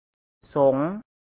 Suppose you wanted to write so4N (rising tone on the vowel) and so2N (high tone).
ສົງ using the su4uN [High Class] "s" symbol   (     is the short vowel "o" and    is the sonorant consonant "N"), since a su4uN class initial consonant followed by a short or long vowel plus a sonorant final consonant is pronounced with a rising tone.